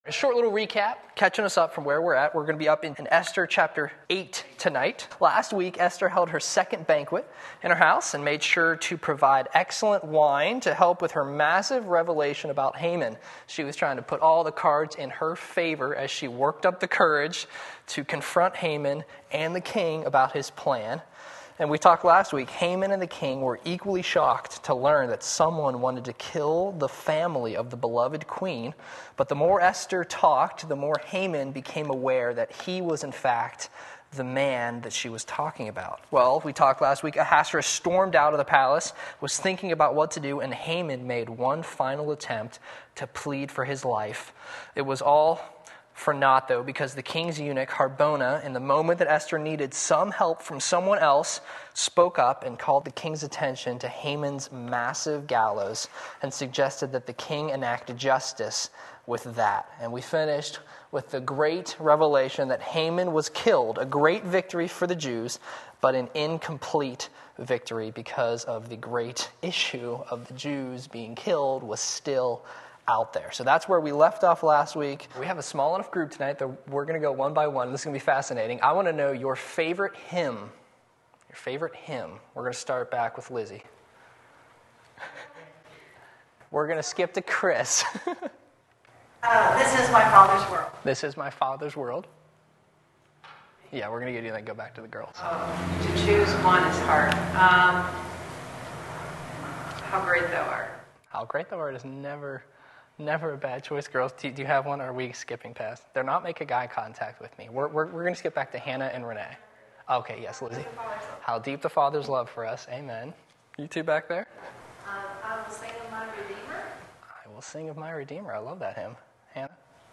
Lesson 7 Esther 8:1-17 Wednesday Evening Service